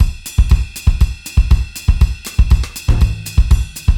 Samba 2
Straight / 120 / 2 mes
SAMBA1 - 120.mp3